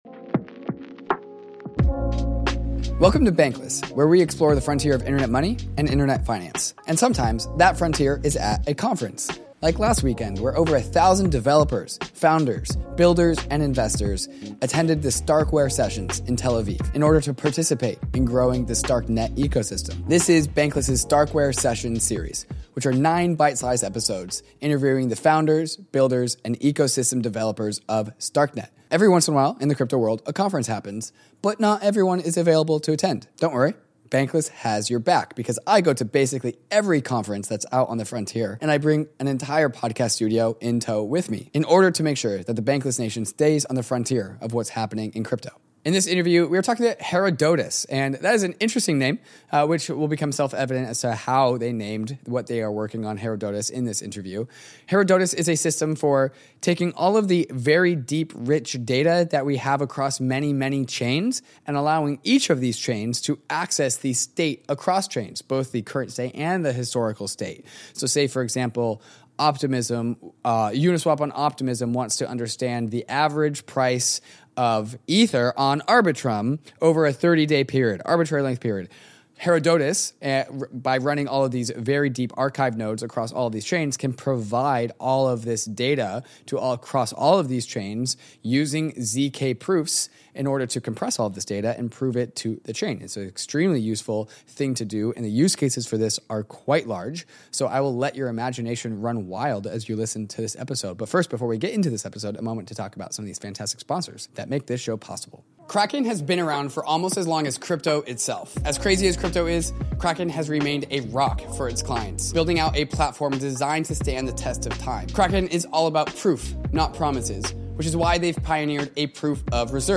Sometimes, the frontier is at a crypto conference. We’re returning from our adventures in Tel Aviv with nine exclusive interviews with some of the key players in the StarkNet space.